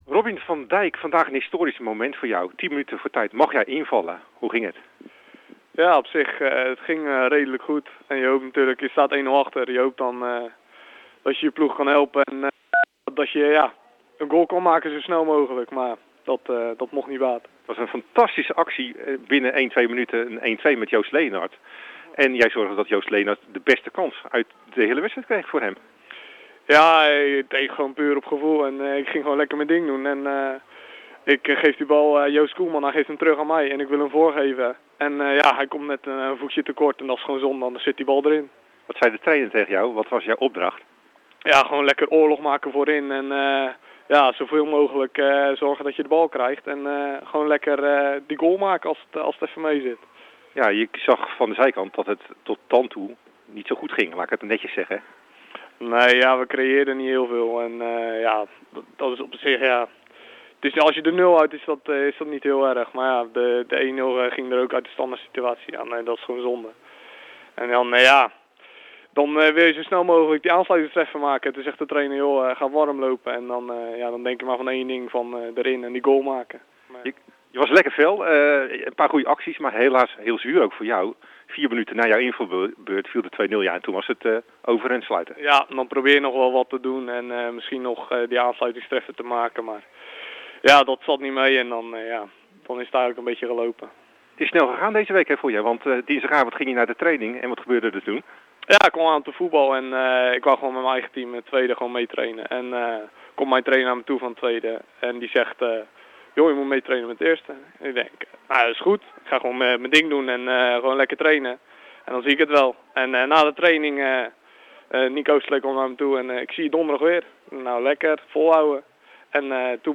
AUDIO: Interview